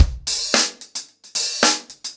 DaveAndMe-110BPM.21.wav